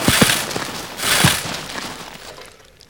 hand mining
shovelclean.wav